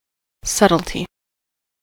subtlety: Wikimedia Commons US English Pronunciations
En-us-subtlety.WAV